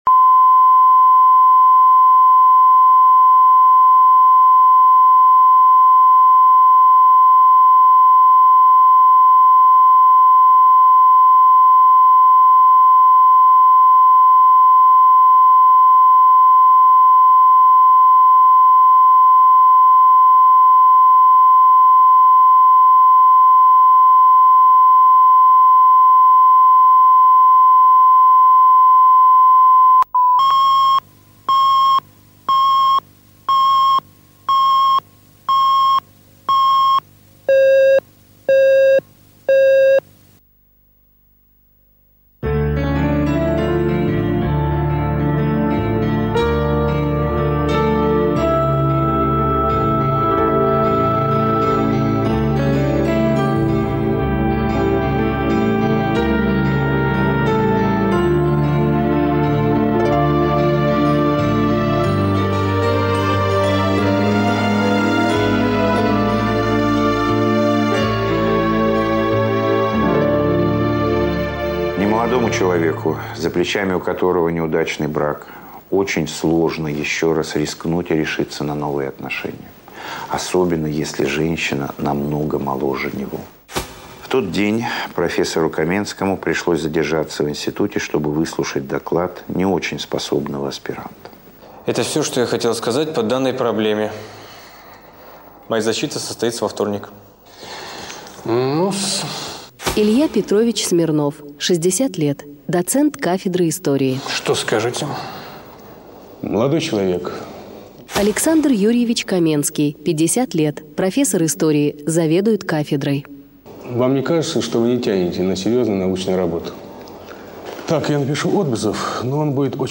Аудиокнига Защита